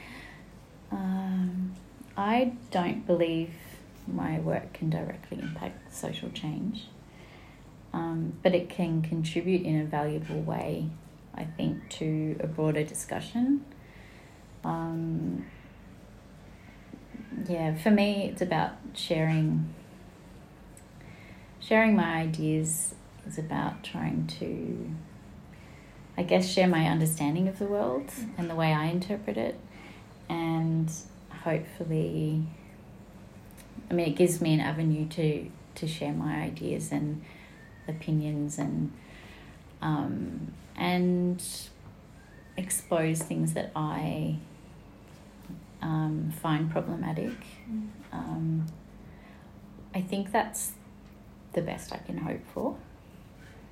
From interview